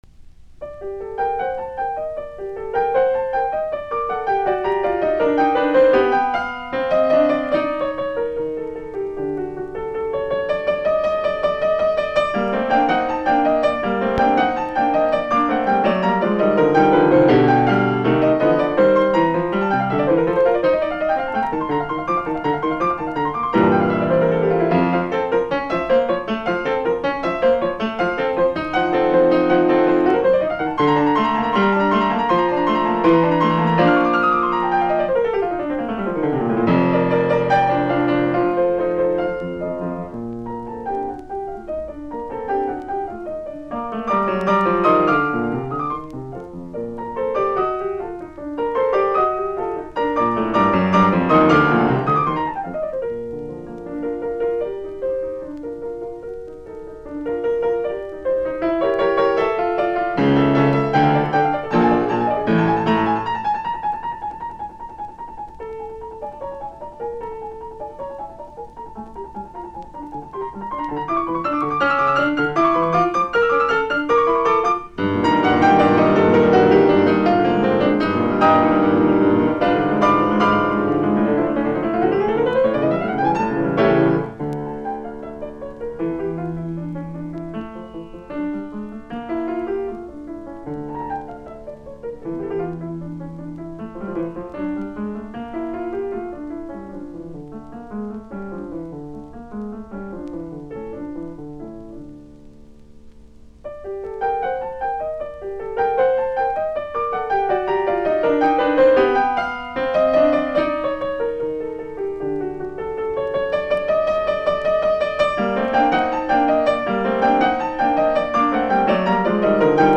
Sonaatit, piano, op34, nro 2, g-molli
Soitinnus: Piano.